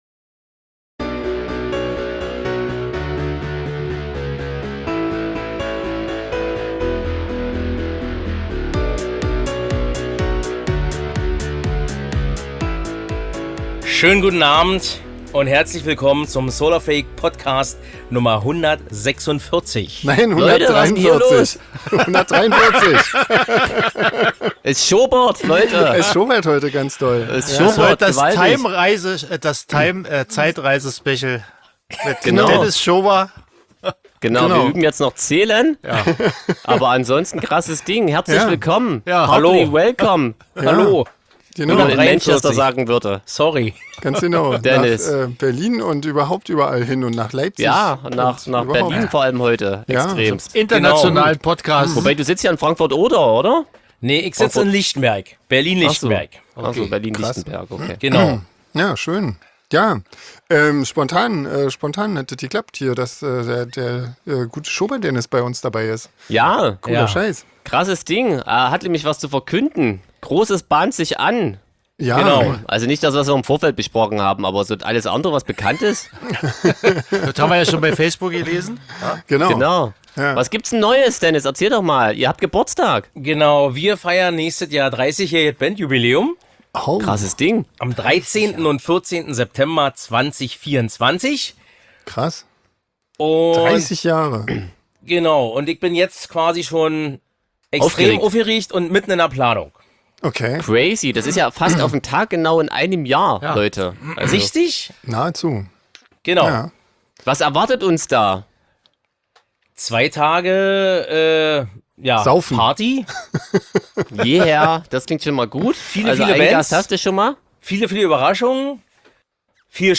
Ansonsten beantworten wir einfach zu viert Eure Fragen. Es geht um Tamtam, Songwünsche, Lieblingsclubs, Lampenfieber und vieles mehr.